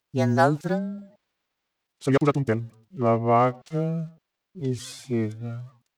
speech-transformed-1
catalan female frequency-scaling frequency-stretching harmonic hps hpsModel reconstruction sound effect free sound royalty free Memes